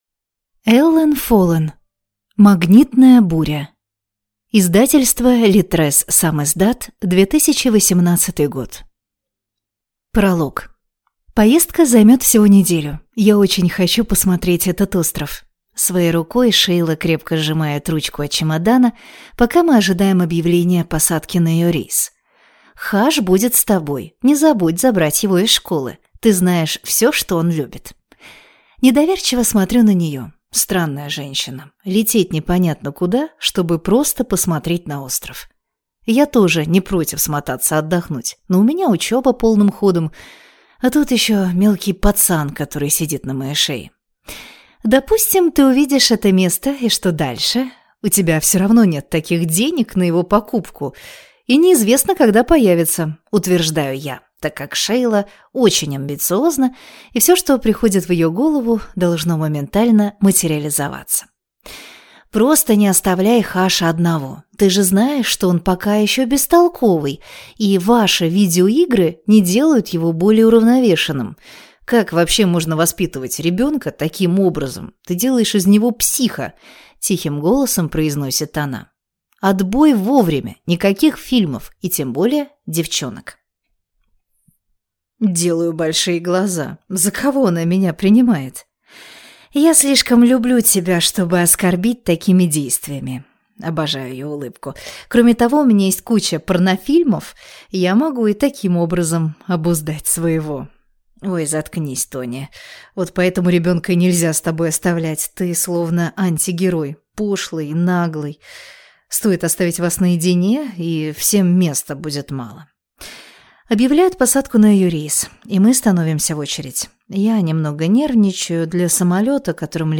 Аудиокнига Магнитная буря | Библиотека аудиокниг